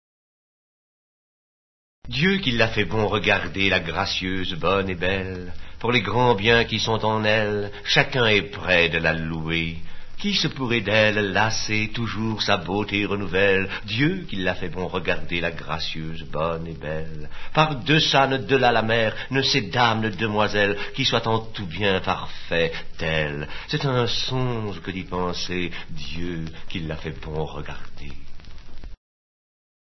/wp-content/uploads/2011/01/dieuquillafaitbon.mp3 dit par Jean VILAR Charles D’ORLÉANS